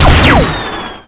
gun2.ogg